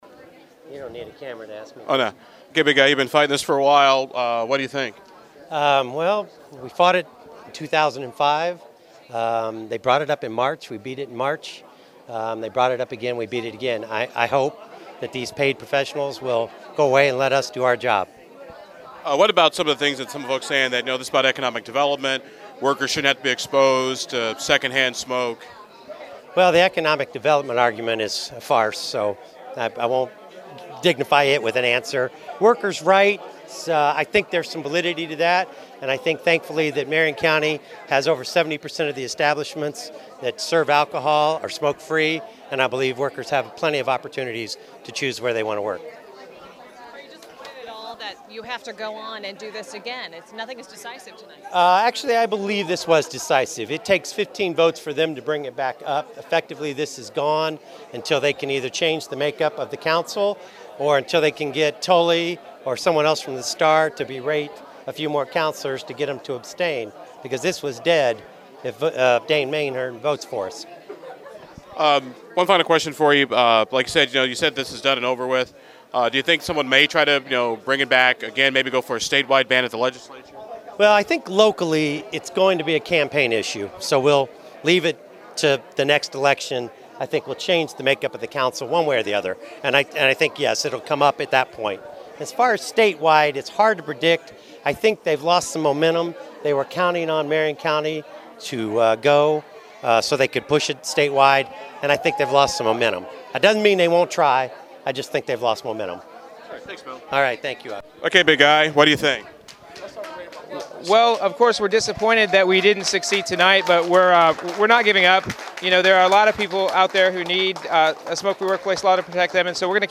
smoking montage
smoking-montage.mp3